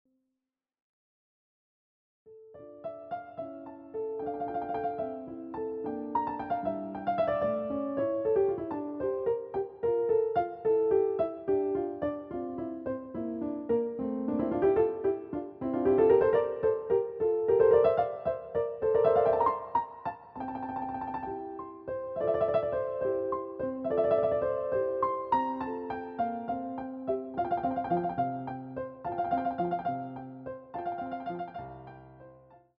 CD quality digital audio Mp3 file
using the stereo sa1mpled sound of a Yamaha Grand Piano.